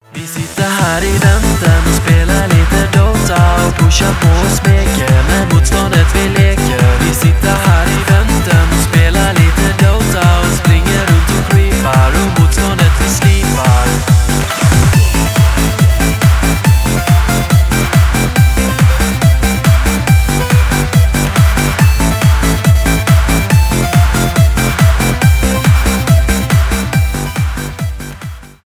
Клубные » Танцевальные